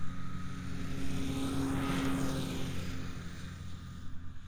DUC Snowmobile Description Form (PDF)
DUC Subjective Noise Event Audio File (WAV)